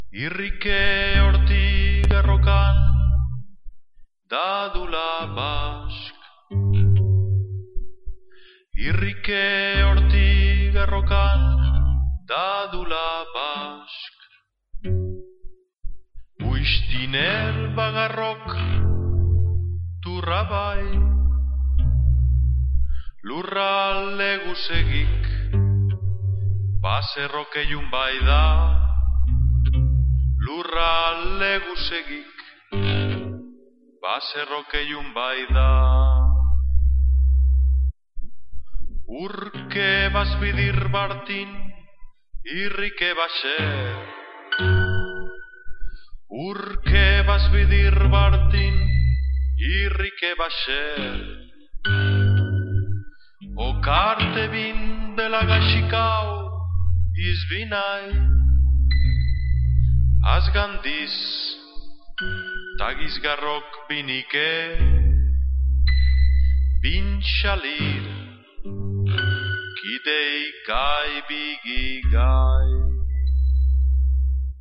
Here is part of a song in a mystery language.